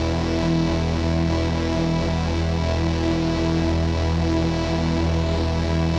Index of /musicradar/dystopian-drone-samples/Non Tempo Loops
DD_LoopDrone5-E.wav